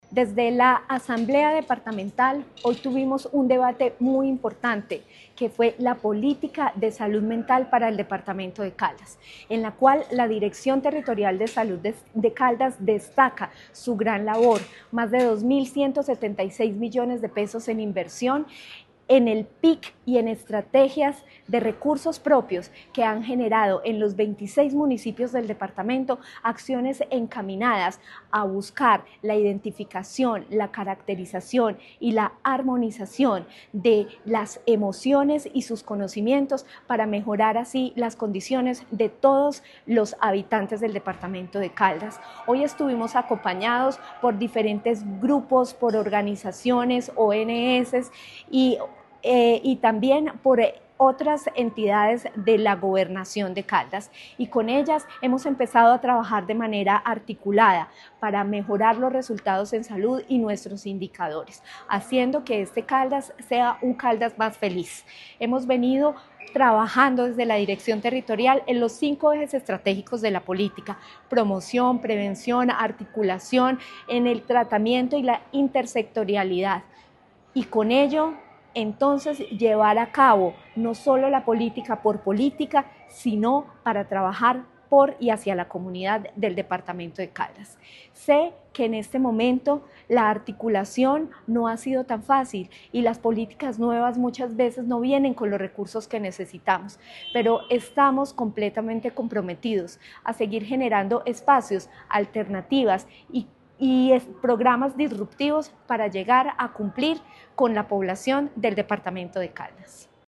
Natalia Castaño Díaz, directora de la DTSC, presentó los avances y retos de la Política de Salud Mental ante la Asamblea Departamental, destacando el compromiso institucional con el bienestar emocional de los caldenses.
AUDIO-NATALIA-CASTANO-DIAZ-DIRECTORA-DTSC-TEMA-POLITICA-SALUD-MENTAL.mp3